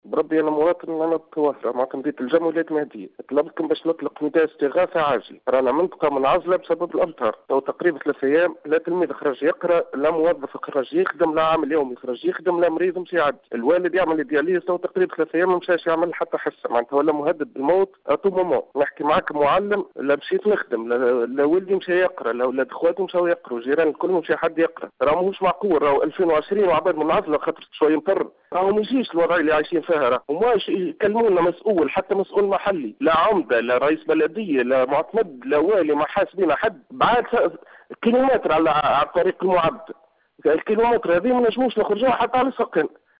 و أكدّ المواطن في اتصال هاتفي بالجوهرة أف أم اليوم الاثنين، عدم تمكّن السُكّان بالمنطقة المذكورة، من الخروج من منازلهم،و عدم التحاق التلاميذ بمدارسهم، مشيرا إلى عدم قدرته على نقل والده لمركز تصفية الدم منذ 3 أيام بسبب انقطاع الطريق بسبب المياه.